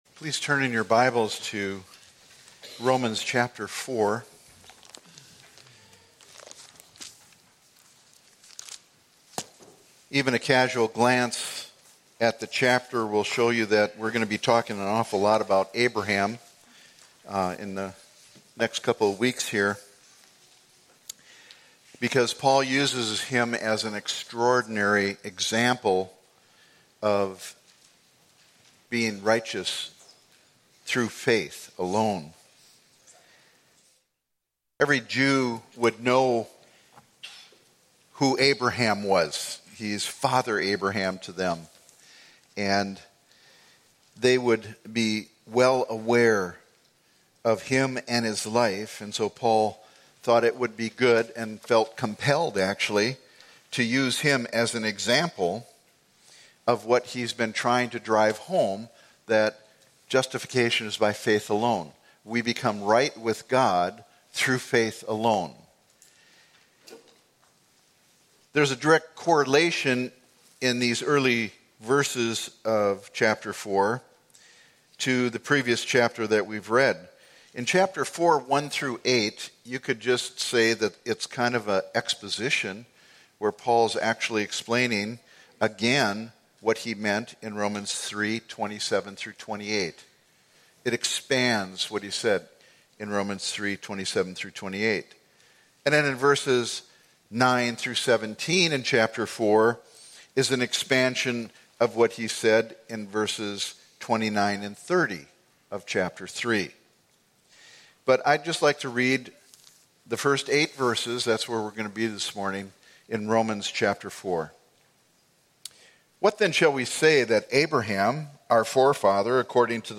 The Extraordinary Example Of Abraham Sermons podcast